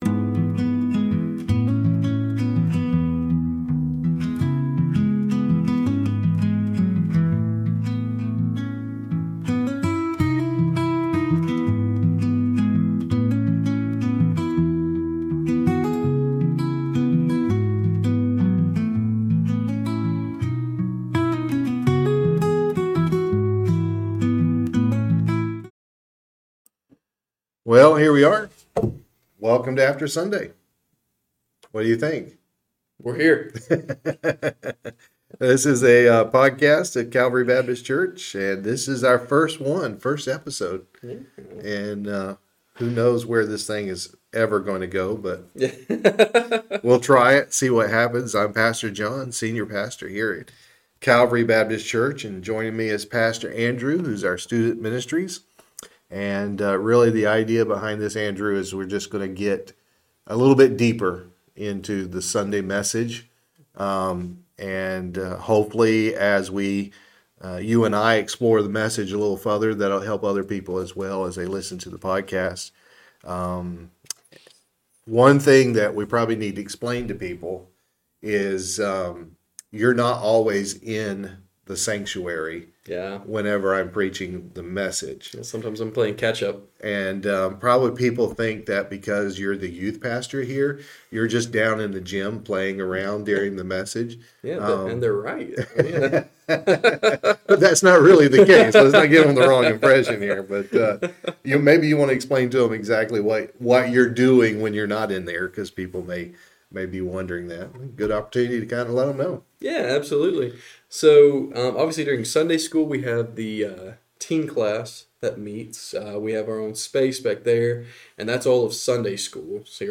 No scripts.
Just two pastors who love the Word, love their church, and love the honest conversation that happens when Sunday morning is over and the real talking can begin.